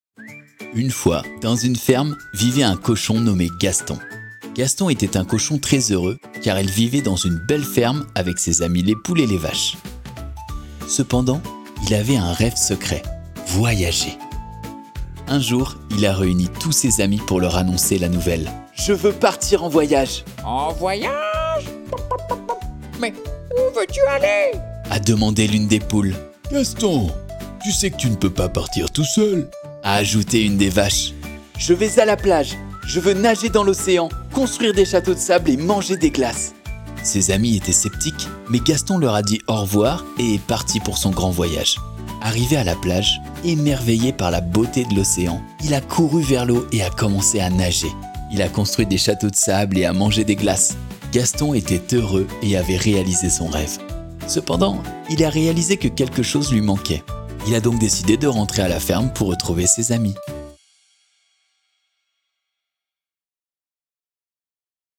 Audio Livre
25 - 50 ans - Baryton-basse